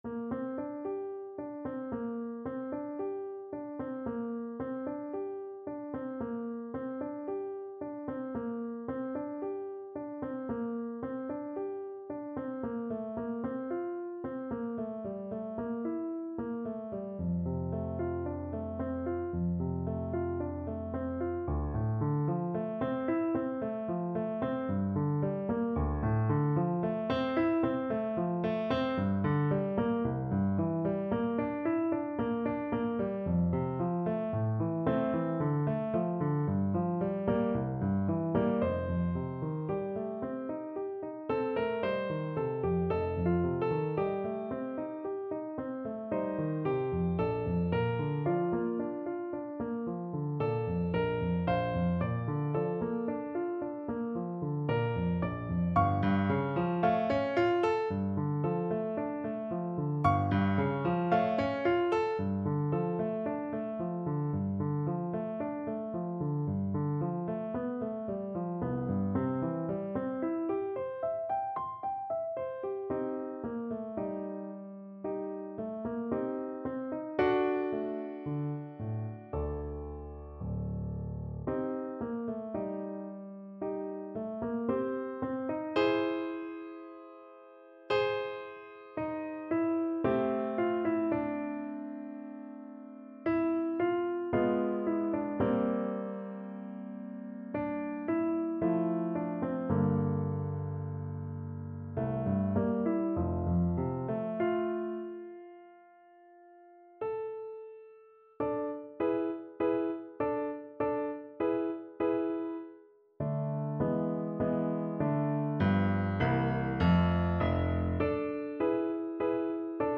4/4 (View more 4/4 Music)
F major (Sounding Pitch) (View more F major Music for Oboe )
Classical (View more Classical Oboe Music)